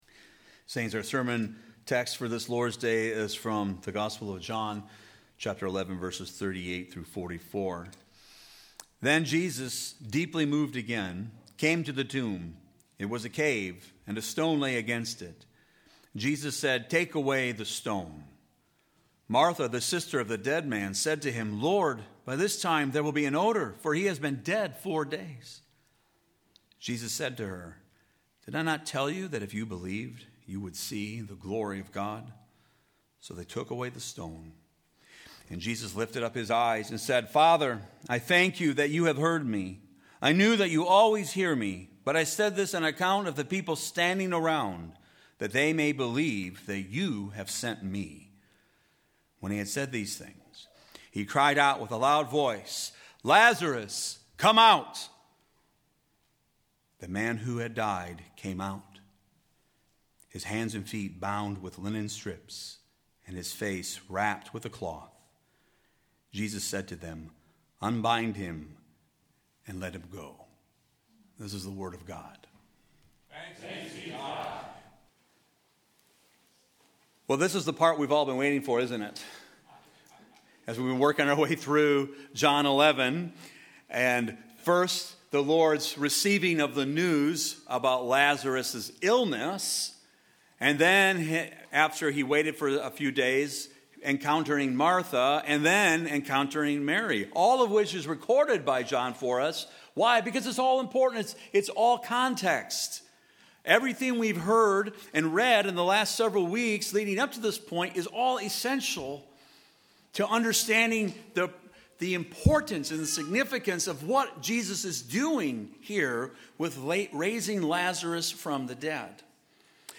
TRC_Sermon-2.8.26.mp3